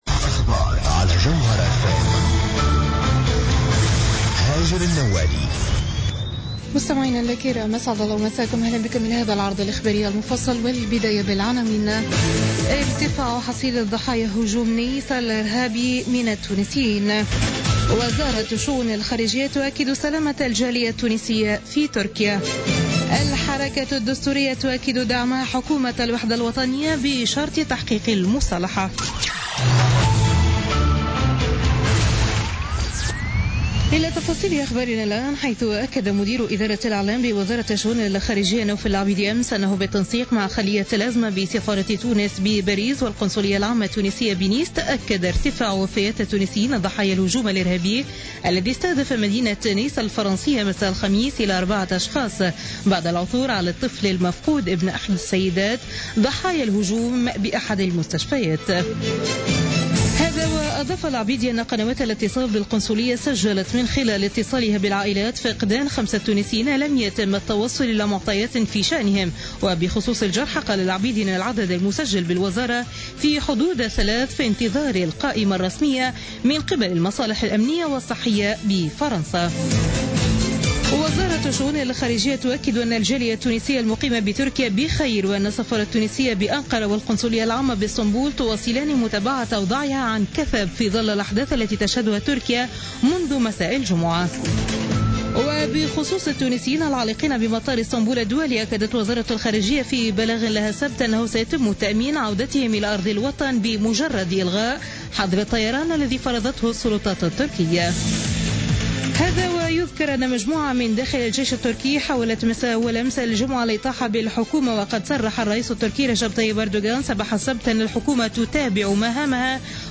نشرة أخبار منتصف الليل ليوم الأحد 17 جويلية 2016